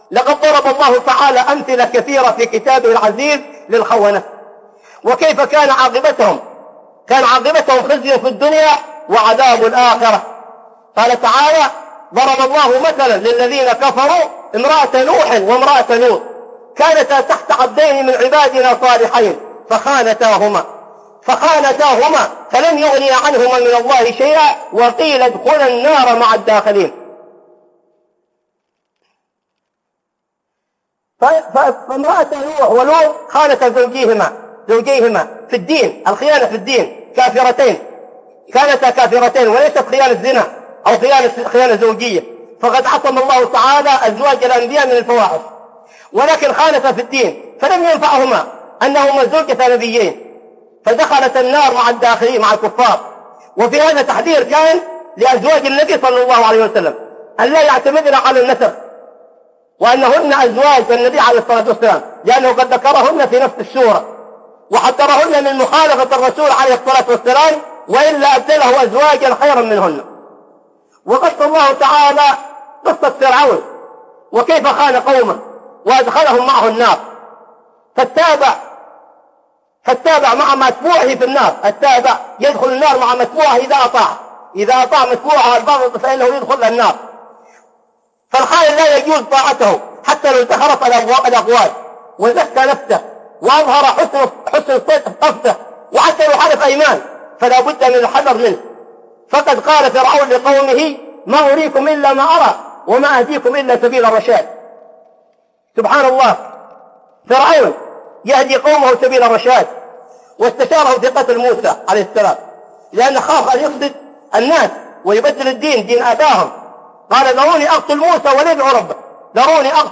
أمثلة على الخونة - خطب